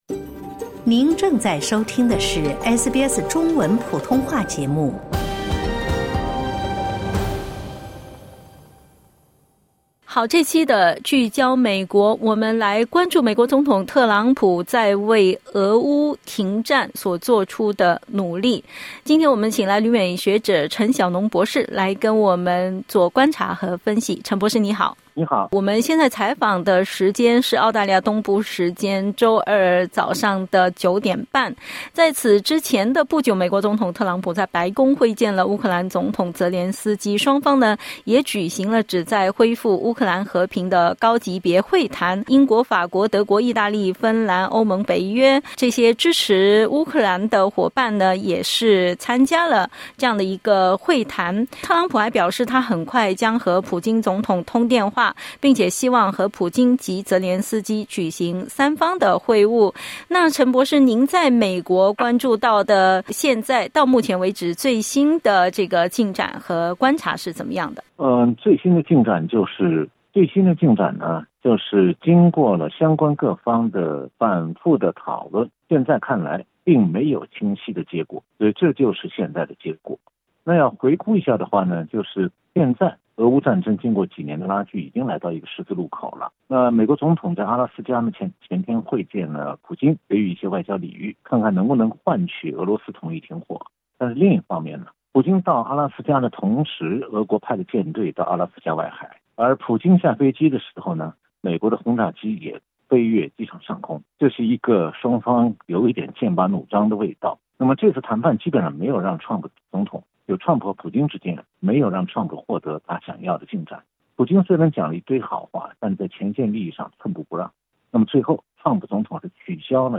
乌克兰需要美国和欧盟“挡在前面”，帮助其达成和平协议以避免来自乌克兰人民的指责？（点击音频收听详细采访）